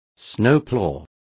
Complete with pronunciation of the translation of snowploughs.